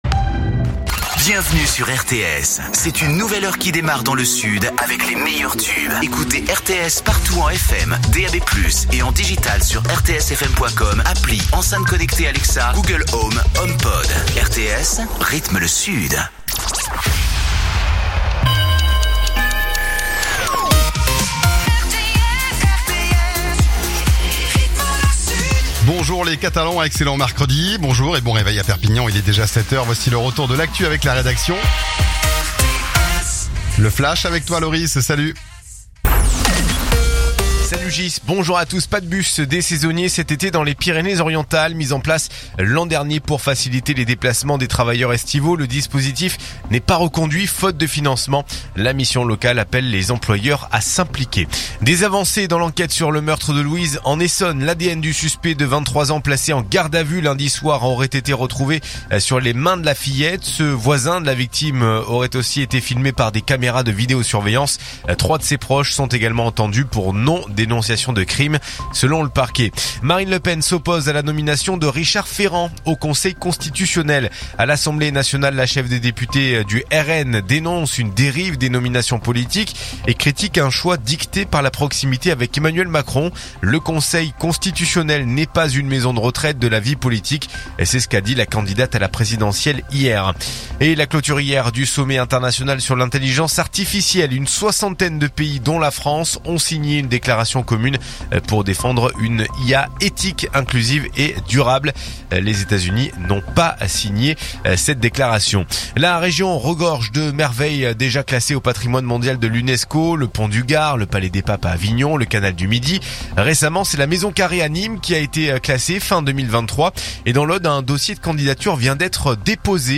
info_perpignan_292.mp3